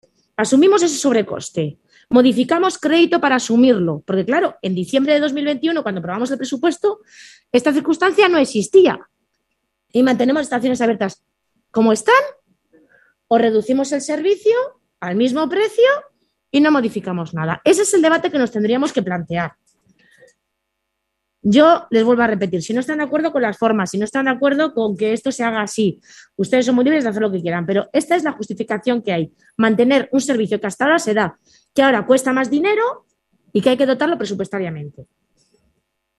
PLENO-3_.mp3